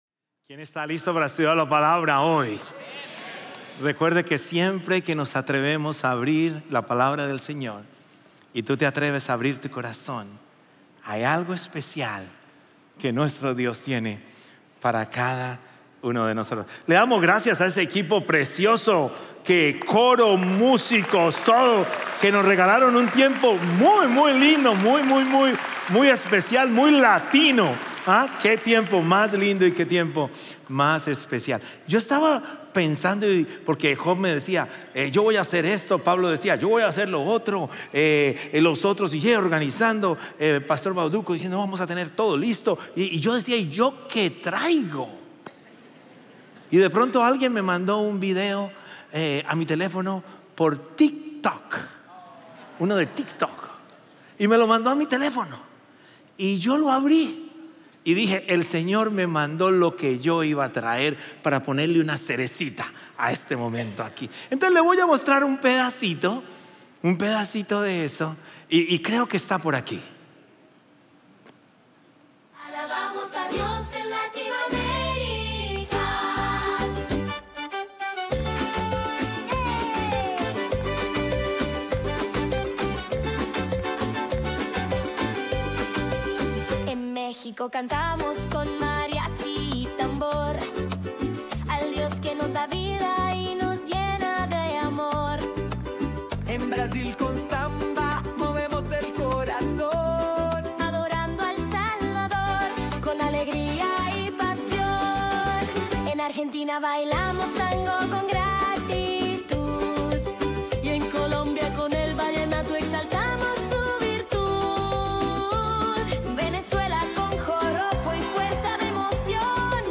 Un mensaje de la serie "Otro - Jersey Village."